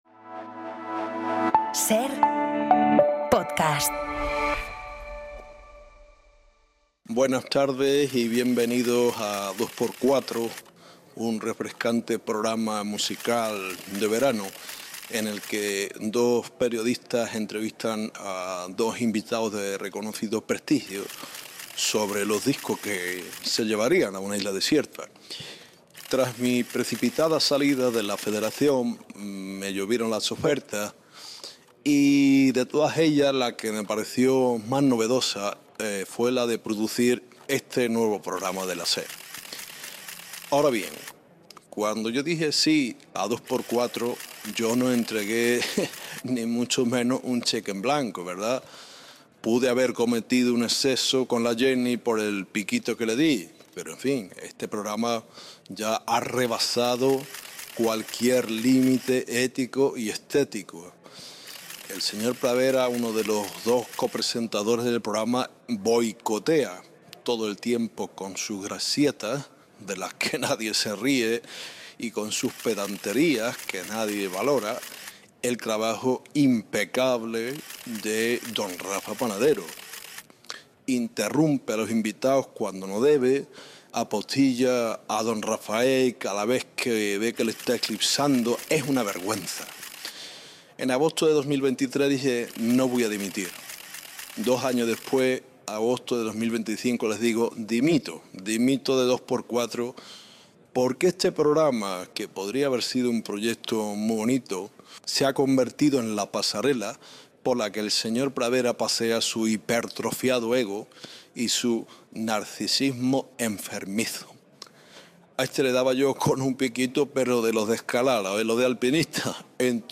Un dúo más que cómico conversa